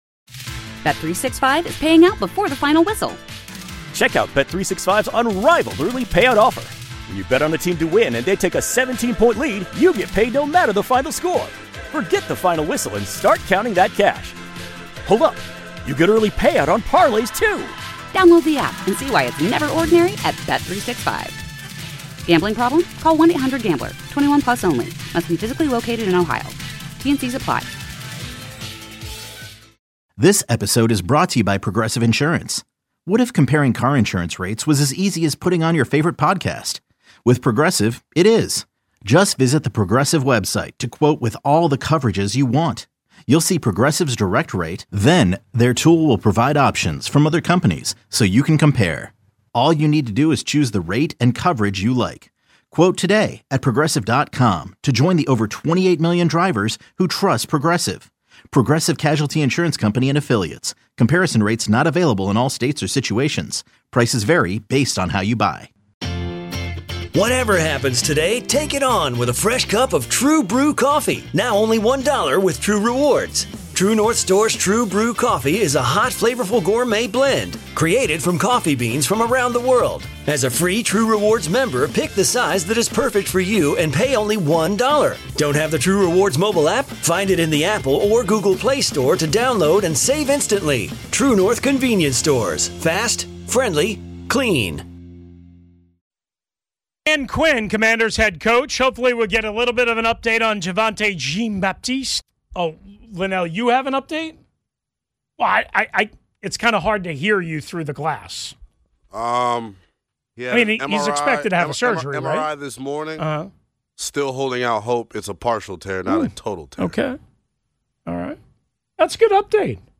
After hearing reactions from a few fans, we turn our attention to Dan Quinn’s press conference, where he provides updates on player injuries and breaks down what went wrong for the Commanders during Sunday’s matchup against the Falcons. The discussion offers insight into the team’s struggles, key moments that shifted the game, and Quinn’s perspective on how Washington can regroup and improve moving forward.